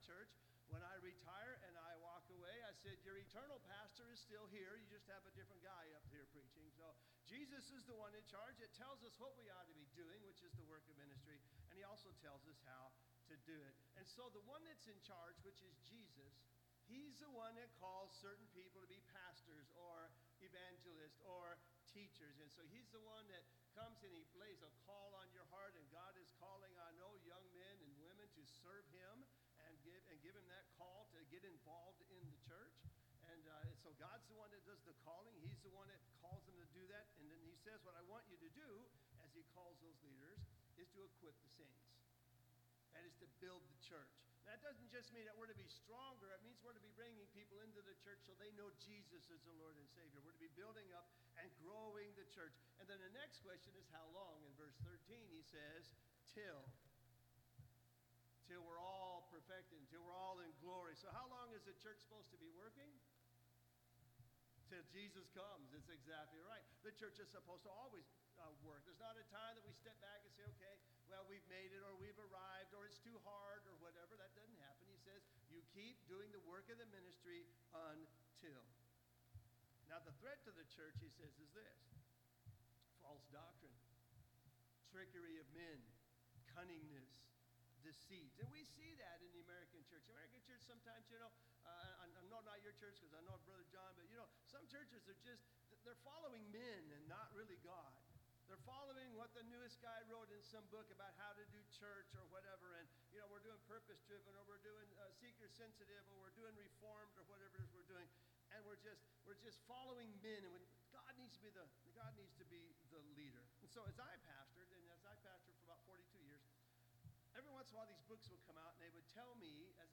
Homecoming Service - Cadet Baptist Church